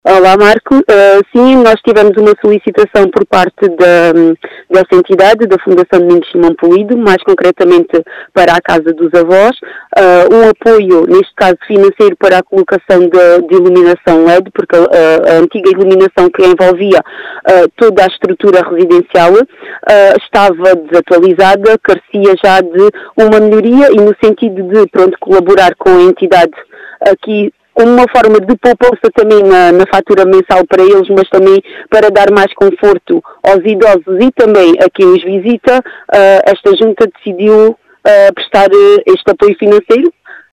As explicações são de Carla Penas, presidente da Junta de Freguesia de Vidigueira.
Carla-Penas.mp3